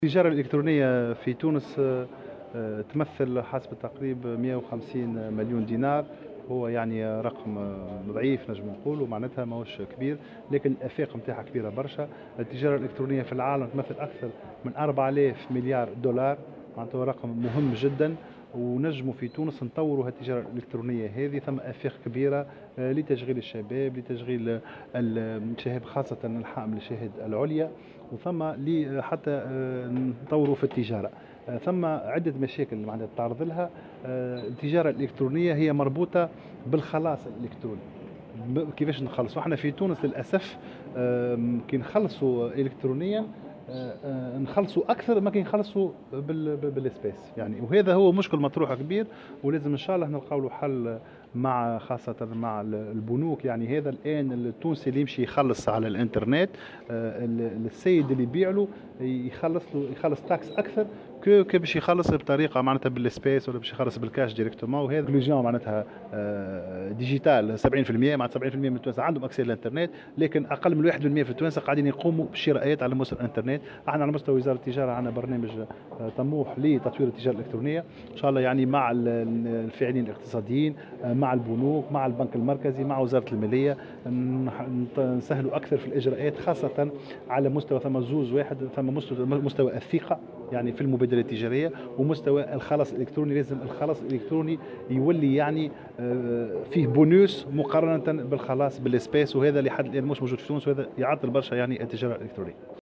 وأضاف في تصريح لمراسلة "الجوهرة أف أم" على هامش ندوة بعنوان " المستهلك التونسي والتجارة الإلكترونية بين الموجود والمنشود" أنه بالإمكان أن يكون لهذا القطاع آفاقا واعدة في تونس لتشغيل العاطلين وخاصة من أصحاب الشهادات العليا، مشيرا إلى أن الوزارة تعمل على تطويره مع الفاعلين الاقتصاديين وتسهيل الإجراءات وتجاوز الصعوبات المتعلقة أساسا بالخلاص الالكتروني.